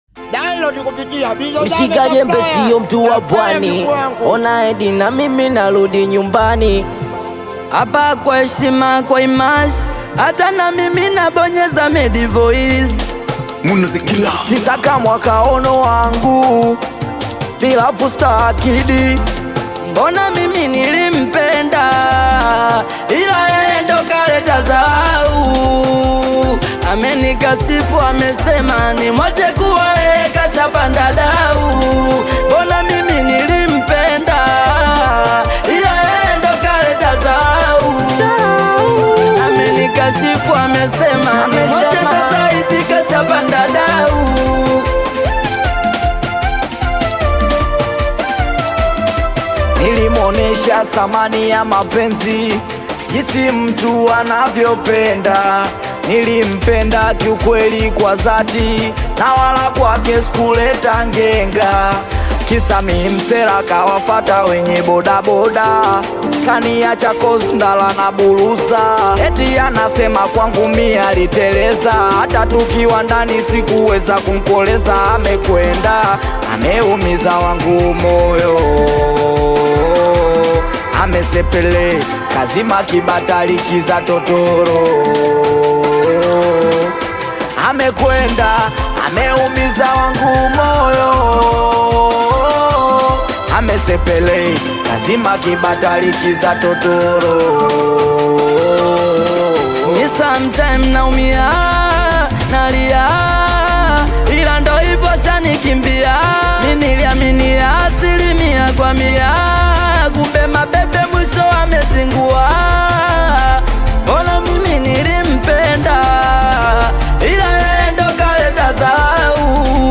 Bongo Flava / Afro-pop
Emotional, romantic, and a bit painful (heartbreak vibe)
Catchy chorus that sticks in your head
Singeli